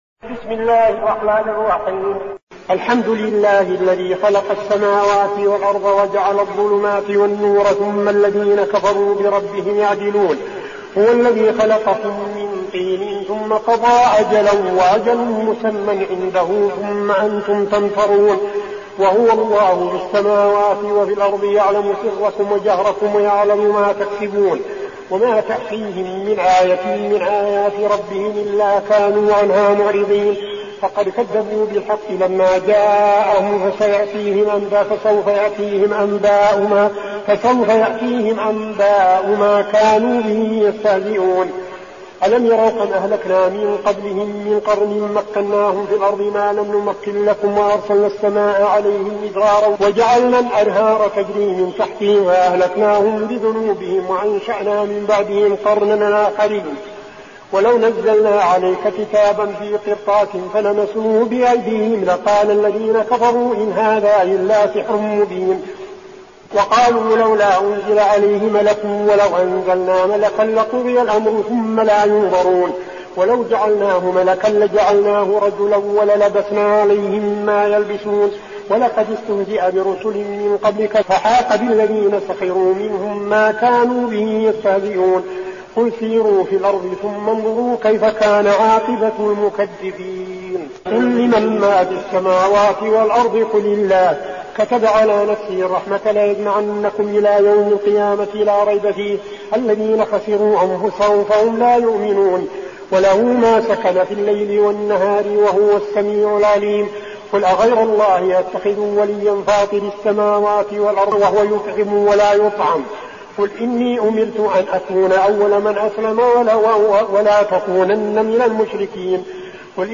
المكان: المسجد النبوي الشيخ: فضيلة الشيخ عبدالعزيز بن صالح فضيلة الشيخ عبدالعزيز بن صالح الأنعام